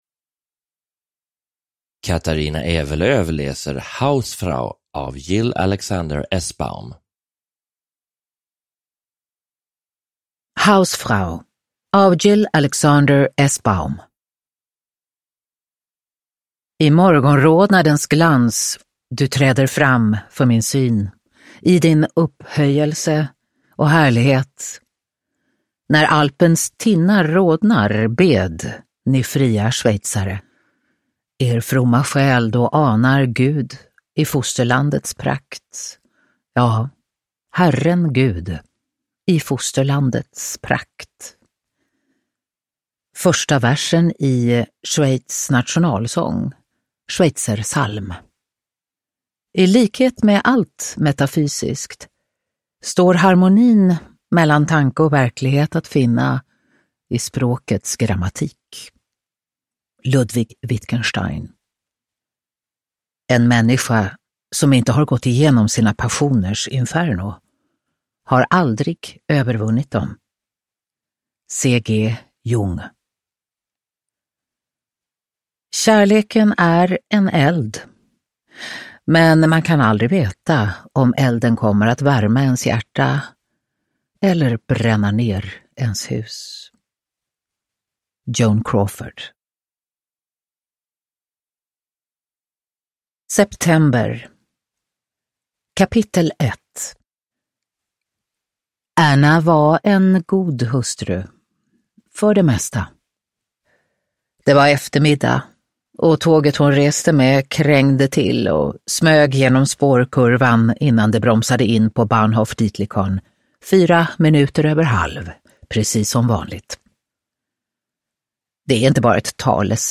Hausfrau – Ljudbok – Laddas ner
Uppläsare: Katarina Ewerlöf